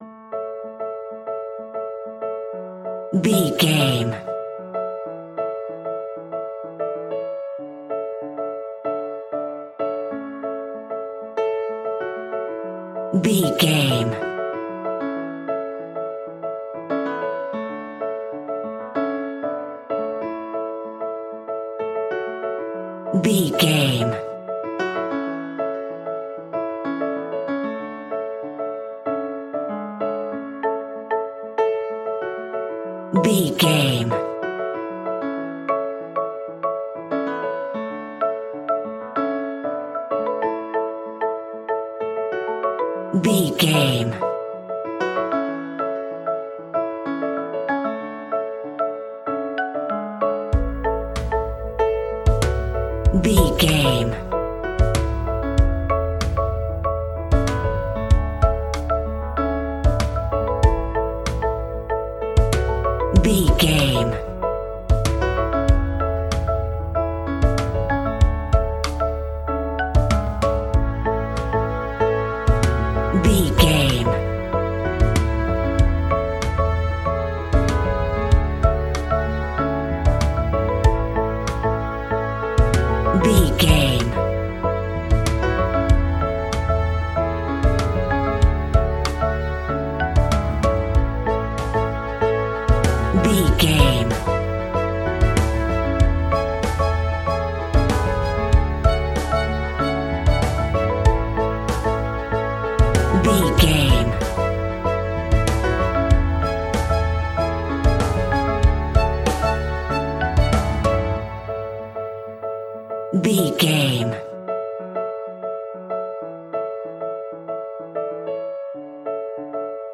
Ionian/Major
pop rock
energetic
uplifting
instrumentals
indie pop rock music
upbeat
groovy
guitars
bass
drums
piano
organ